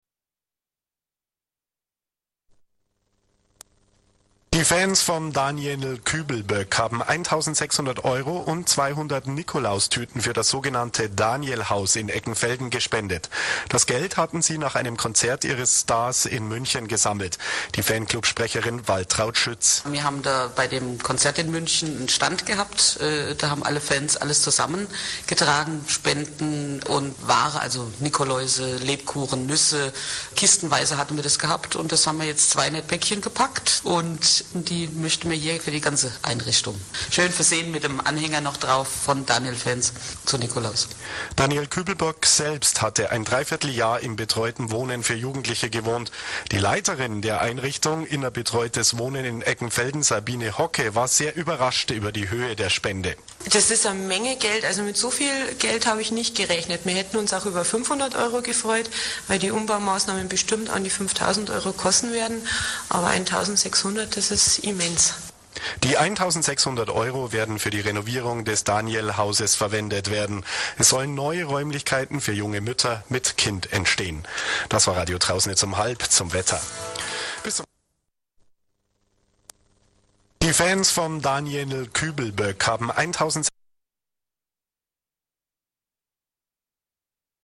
zum Radiointerview: